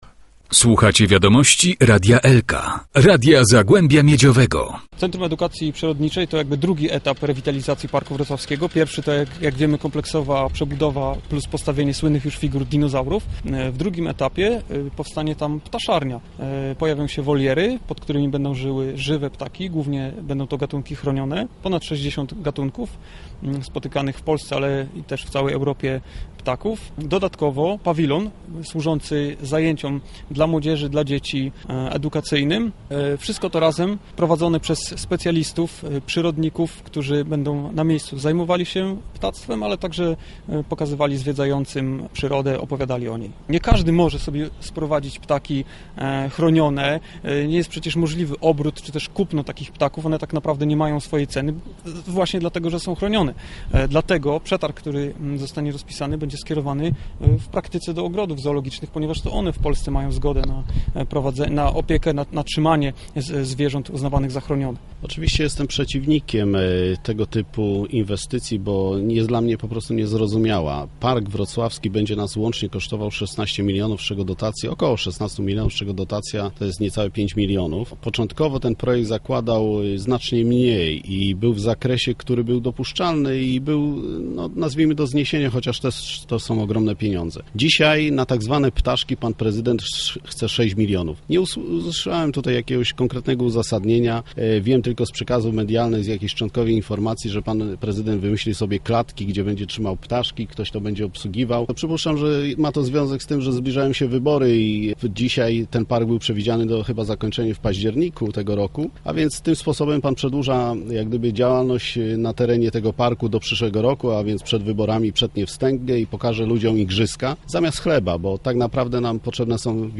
Krzysztof Olszowiak, szef Porozumienia Samorządowego, opozycyjnego klubu w Radzie Miejskiej: